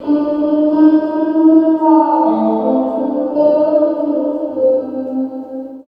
134 GTR 6 -R.wav